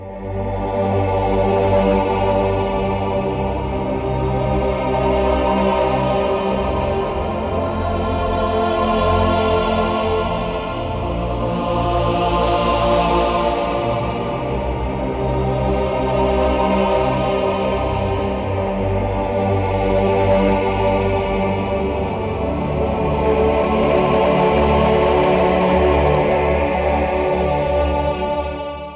choir.wav